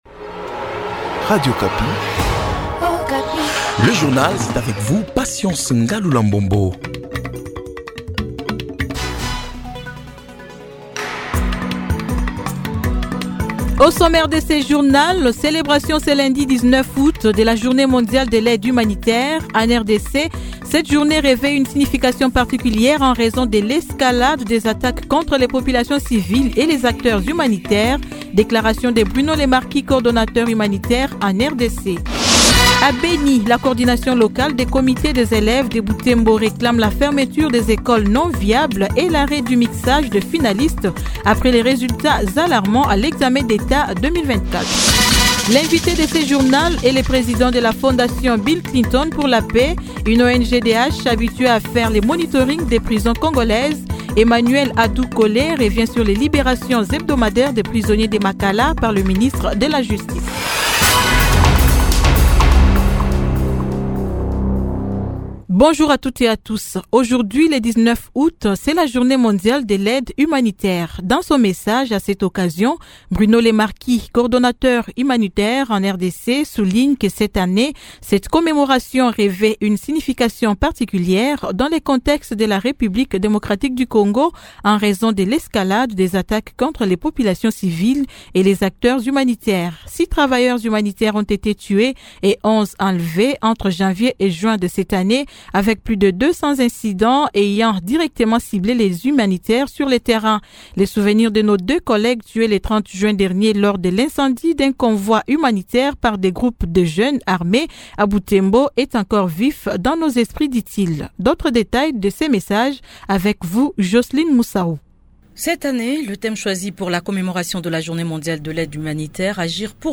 Journal 08H00